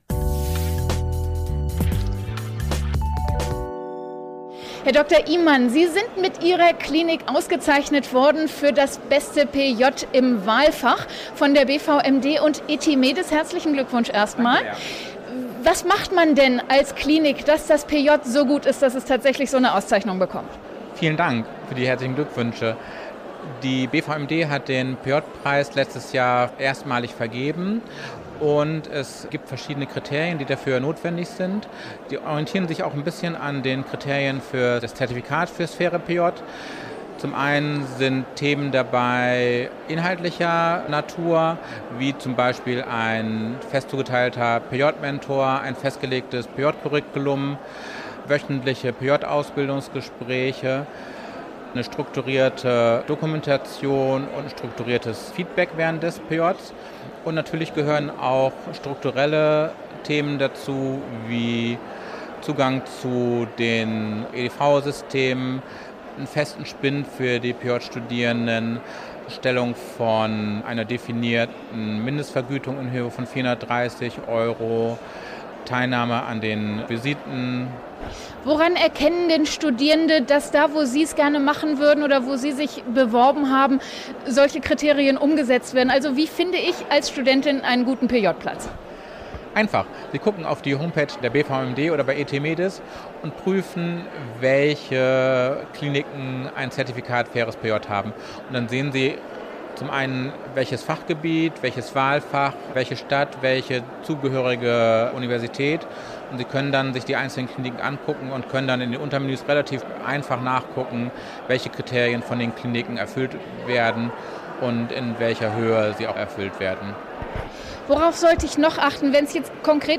Das Interview wurde am Rande des Operation Karriere-Events in Berlin am 23. November 2024 aufgezeichnet.